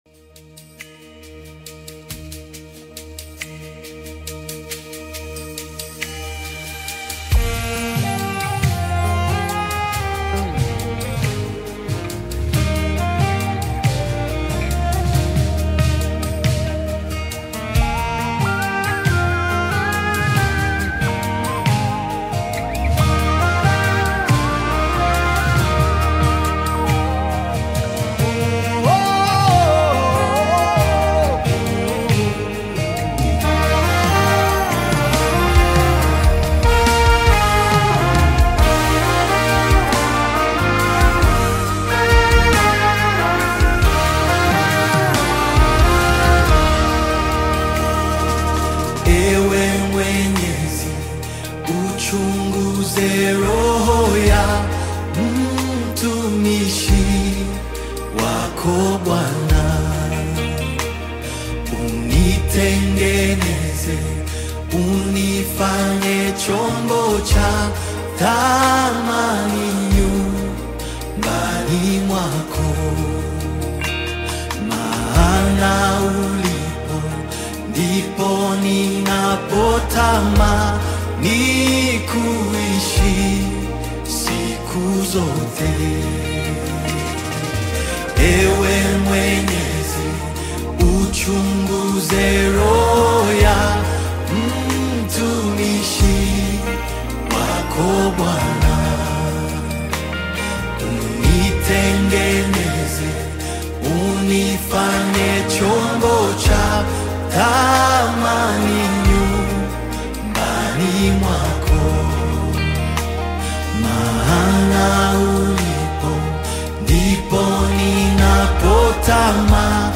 Latest Rwanda Afro-Beats Single (2026)
Genre: Afro-Beats